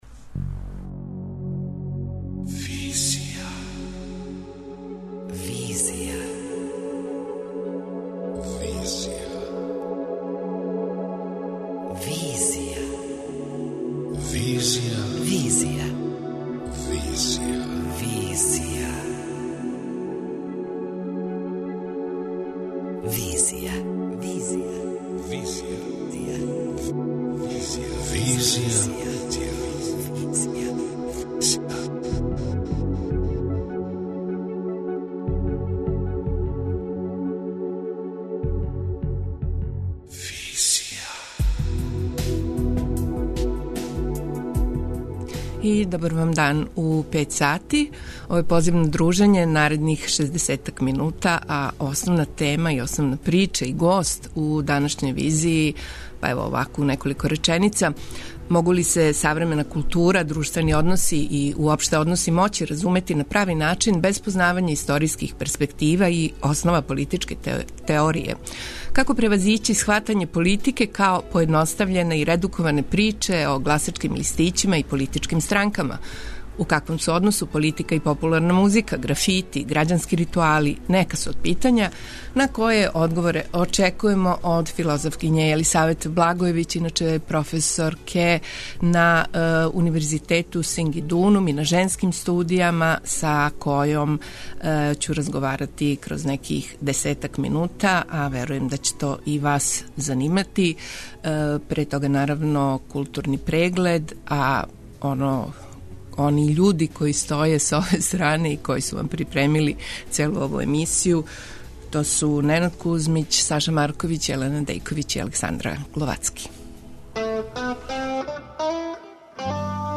преузми : 28.22 MB Визија Autor: Београд 202 Социо-културолошки магазин, који прати савремене друштвене феномене.